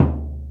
TSW SURDO.wav